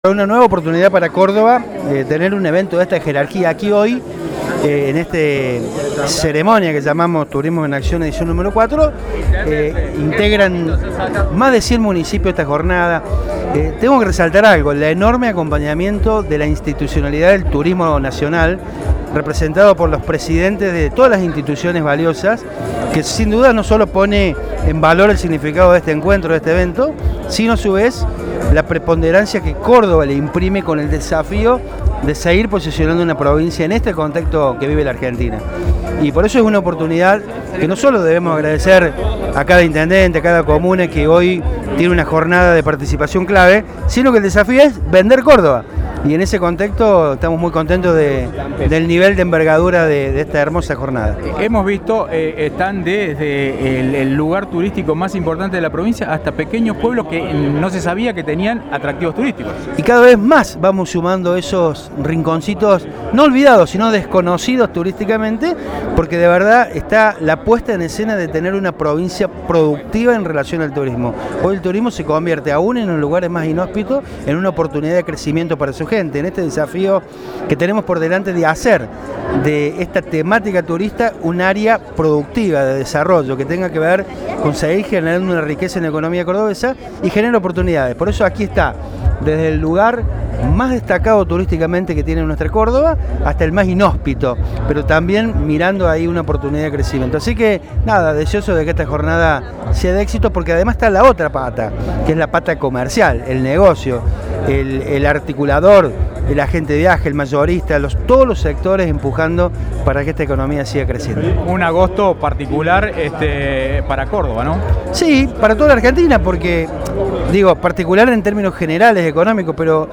Este jueves Córdoba vivió la realización de la cuarta edición de Turismo en Acción, el evento que reunió en un solo lugar a toda la cadena de valor del sector.
Audio: Darío Capitani (Presidente Agencia Córdoba Turismo).